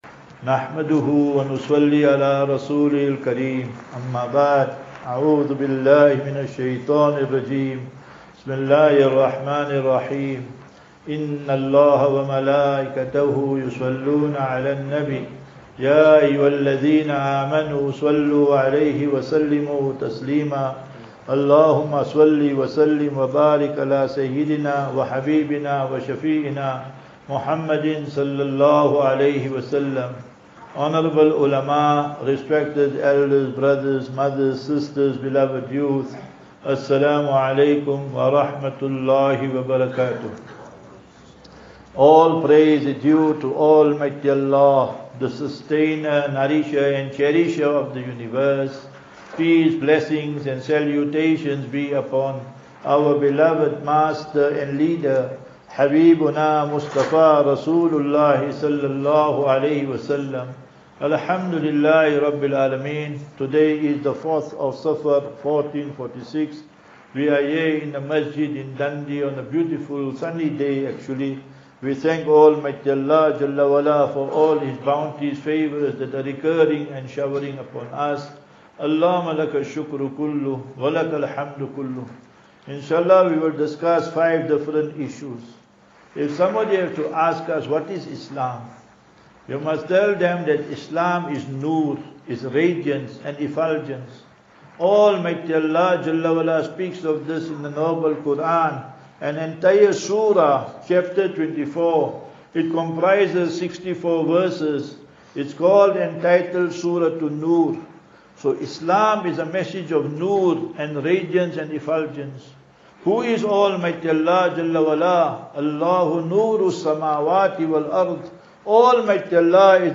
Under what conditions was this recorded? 9 Aug 09 August 2024 – Jumah Lecture at Dundee Masjid - Topic - What is Islam?